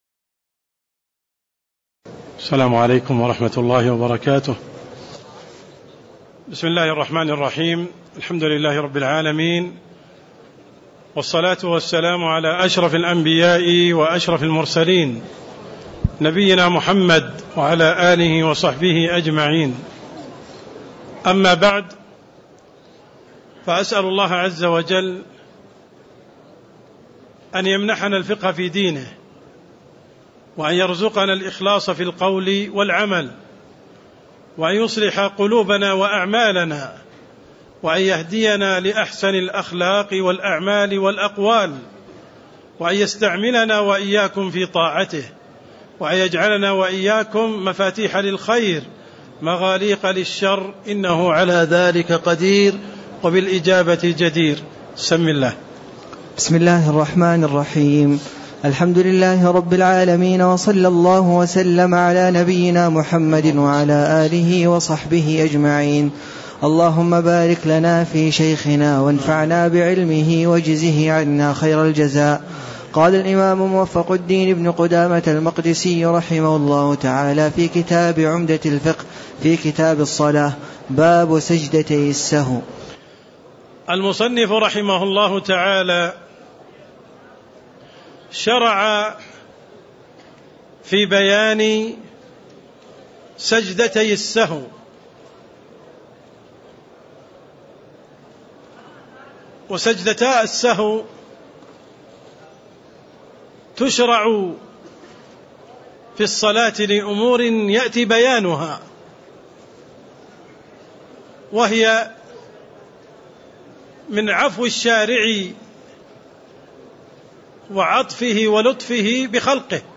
تاريخ النشر ١٤ ربيع الثاني ١٤٣٦ هـ المكان: المسجد النبوي الشيخ: عبدالرحمن السند عبدالرحمن السند باب سجدتي السهو (11)